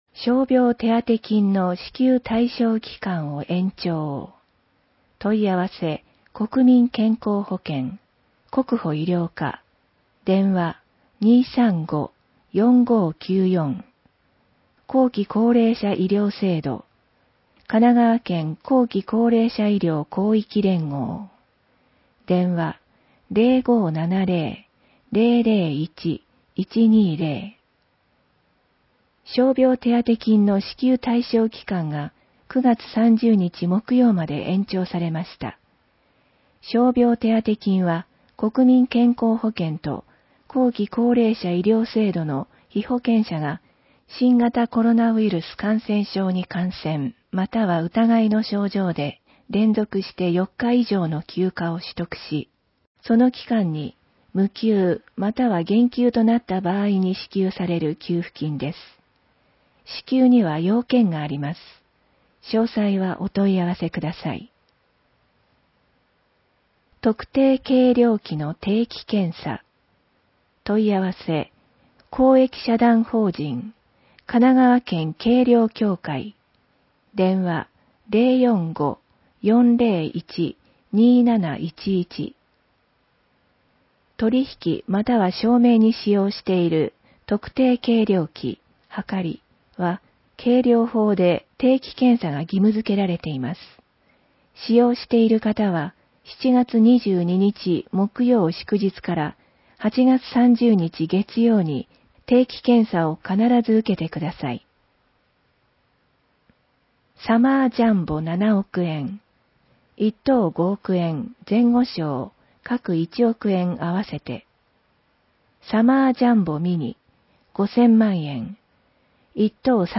広報えびな 令和3年7月1日号（電子ブック） （外部リンク） PDF・音声版 ※音声版は、音声訳ボランティア「矢ぐるまの会」の協力により、同会が視覚障がい者の方のために作成したものを登載しています。